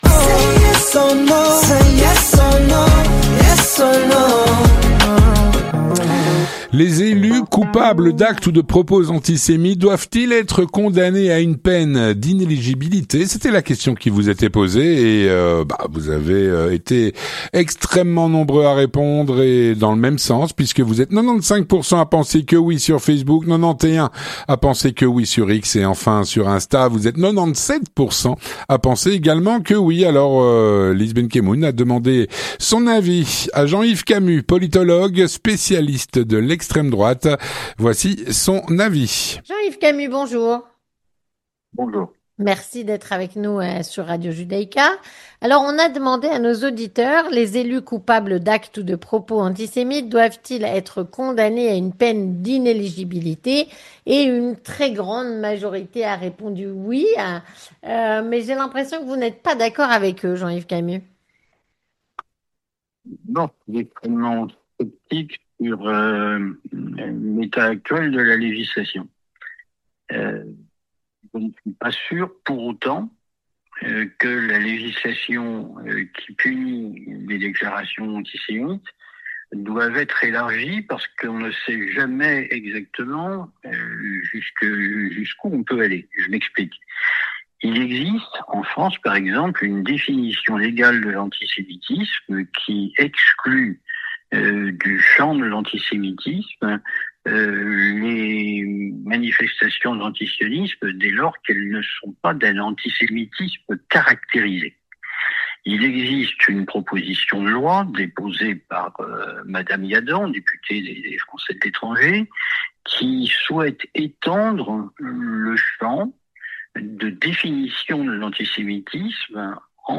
politologue et spécialiste de l’extrême-droite, répond à la "Question Du Jour".